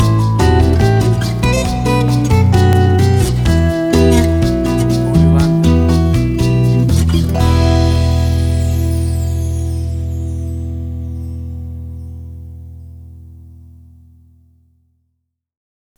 Tempo (BPM): 76